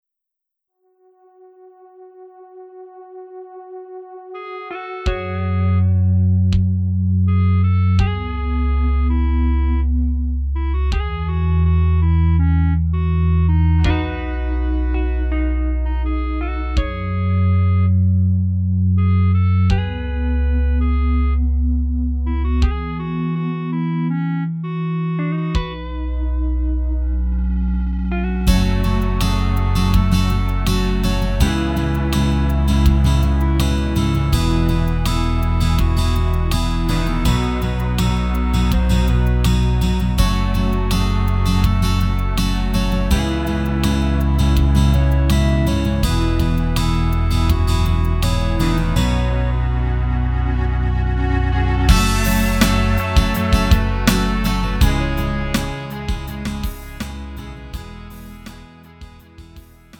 음정 원키 3:19
장르 가요 구분 Lite MR